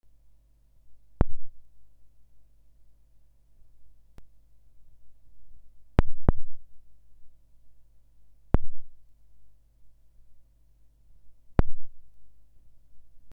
Периодические щелчки в микрофоне
Микрофон Samson CL7, карточка Roland Rubix 24. При записи в микрофон периодически возникают щелчки, иногда пореже, иногда почаще.
Щелчки происходят в самом тракте, никак не зависят от подключения к ПК (отдельная пара карта-мик, проблема та же) С другим микрофоном проблем никаких.